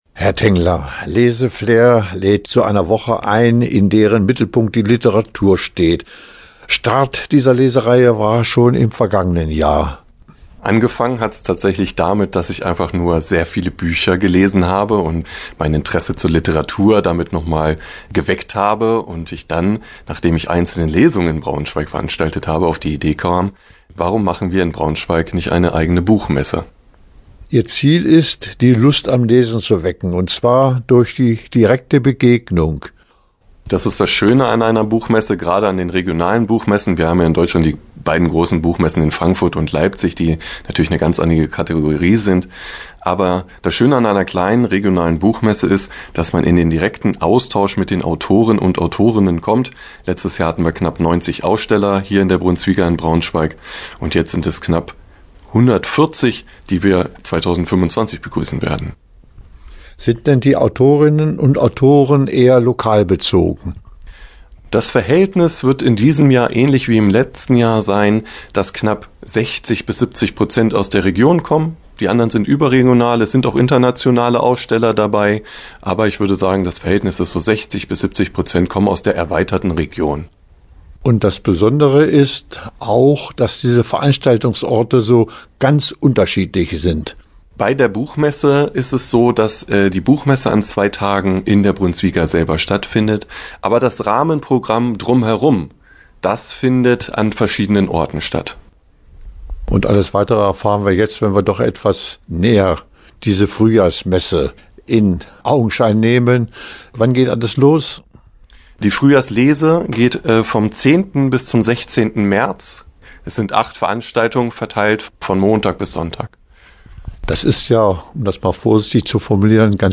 Interview-Leseflair-Fruehjahr.mp3